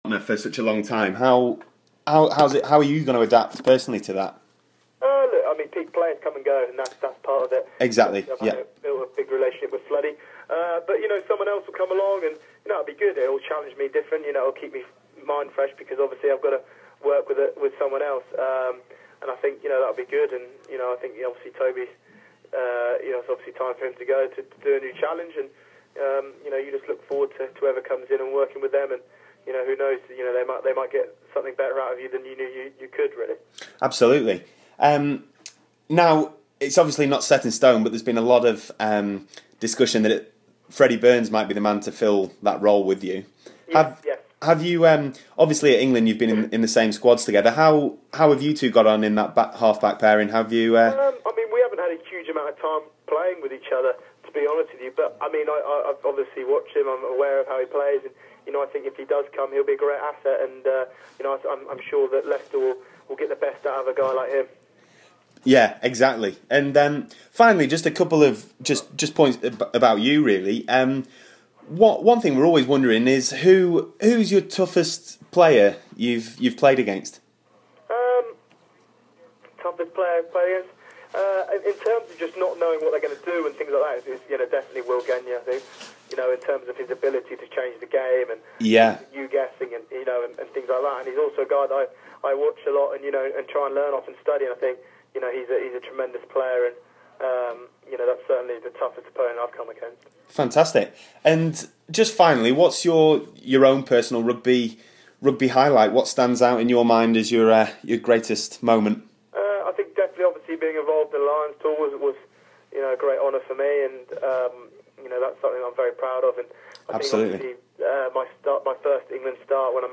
TRU Exclusive Interview with Ben Youngs (PART 2)